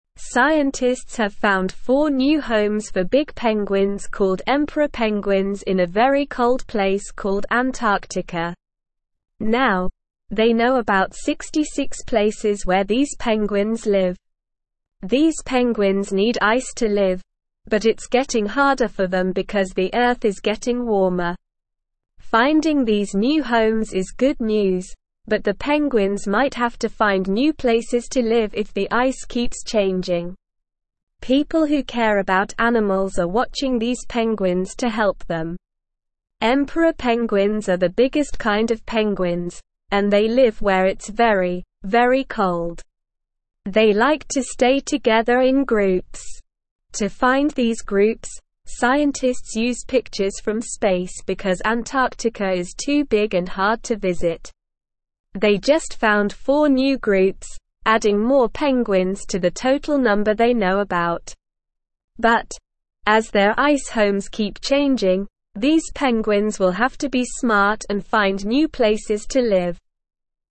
Slow
English-Newsroom-Beginner-SLOW-Reading-New-Homes-Found-for-Big-Penguins-in-Antarctica.mp3